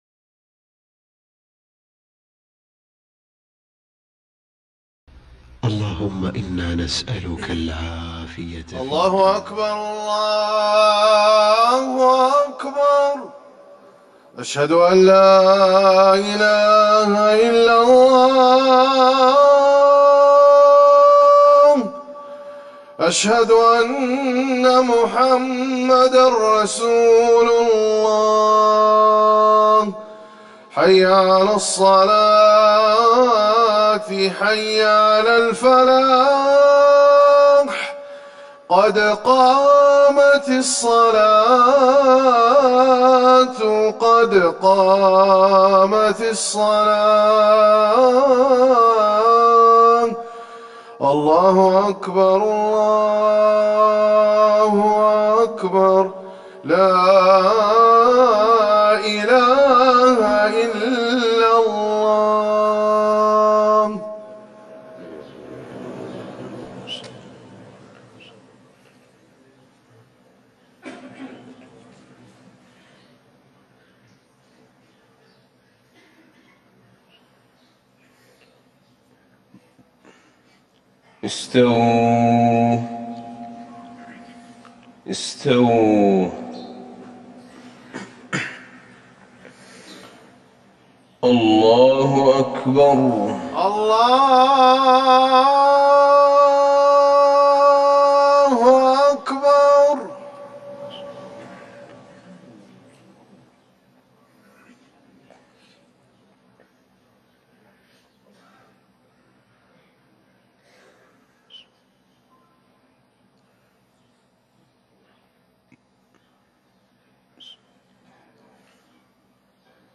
صلاة الفجر ١٦ جمادي الاولى ١٤٤١هـ سورة الصف Fajr prayer 4-1-2020 from Surah Al-Saff > 1441 🕌 > الفروض - تلاوات الحرمين